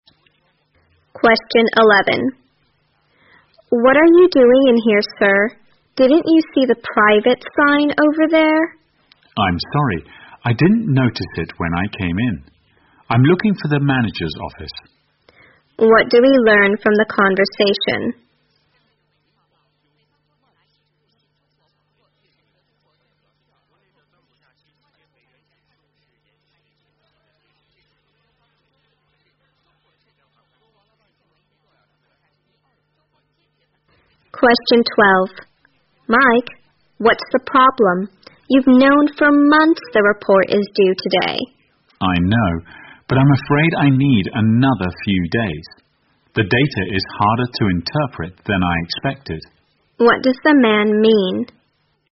在线英语听力室194的听力文件下载,英语四级听力-短对话-在线英语听力室